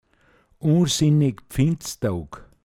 pinzgauer mundart
u(n)sinneg Pfinståog, m. Donnerstag vor Faschingsonntag